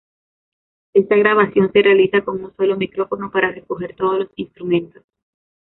Мужской
mi‧cró‧fo‧no